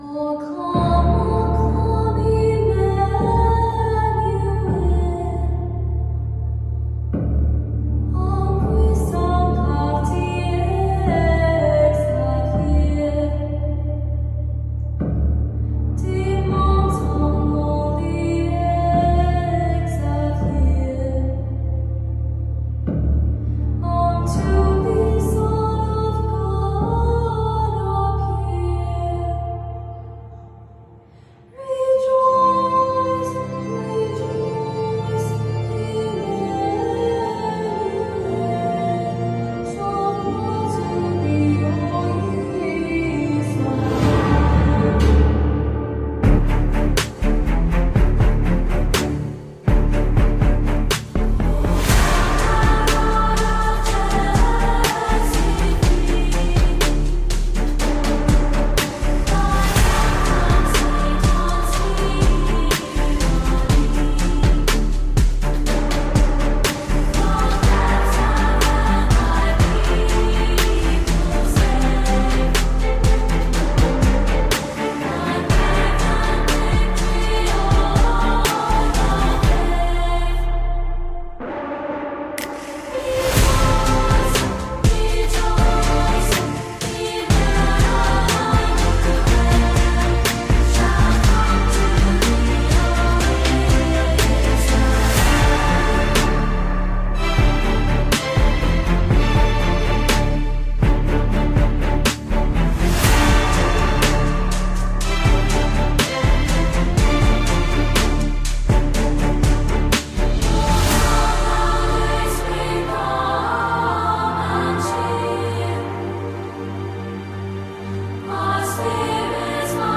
超强压迫感震撼背景音乐BGM